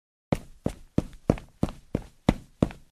任务：实装和完善部分音效，修复场景问题 1.增加走路时的脚步声音效 2.将Listener转移到玩家身上防止立体声出现问题 3.删除了跑步扬尘效果 4.增加了跑步时的脚步声音效 5.加快了跑步动画为原来的1.2倍，使之步频加快，看起来更加真实。
跑步.wav